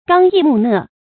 剛毅木訥 注音： ㄍㄤ ㄧˋ ㄇㄨˋ ㄣㄜˋ 讀音讀法： 意思解釋： 剛：堅強；毅：果決；木：質樸；訥：說話遲鈍，此處指言語謹慎。